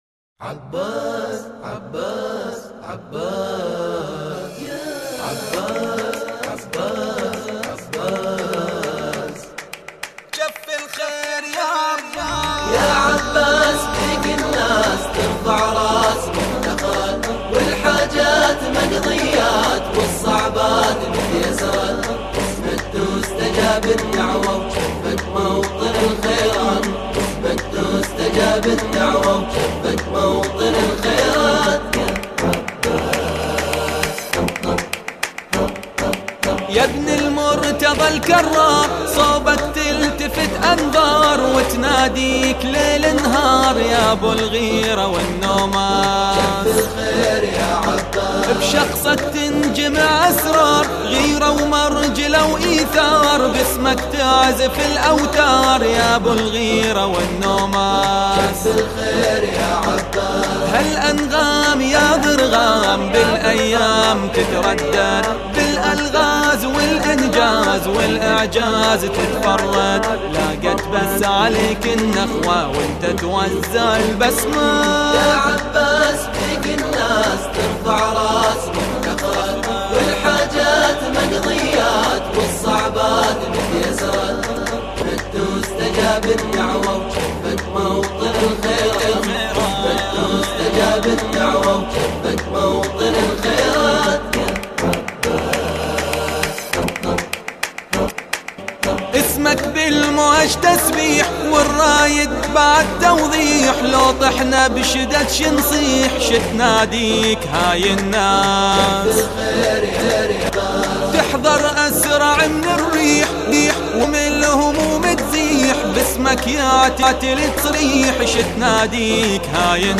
الرادود